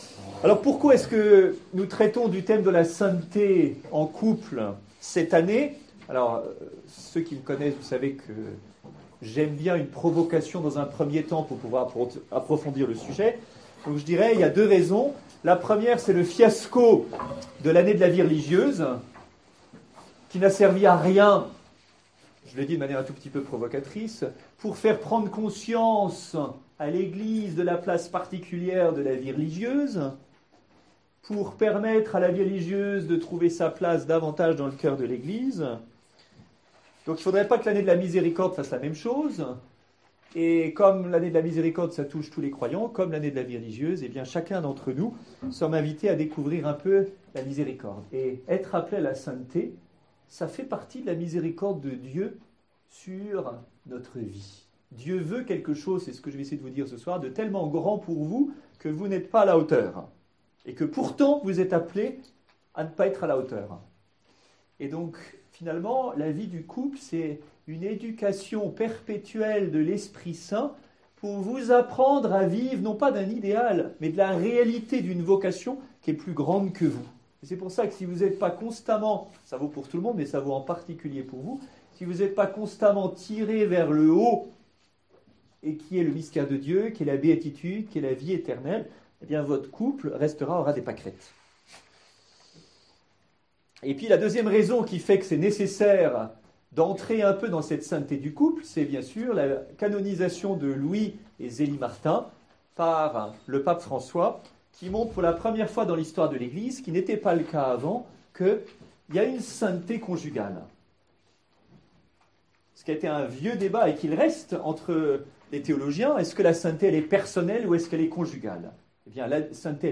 Conférence FSJC 25/11/15 : Homme et Femme, Il les créa (Gn 1,27) Apprendre à vivre de nos différences en vue de la sainteté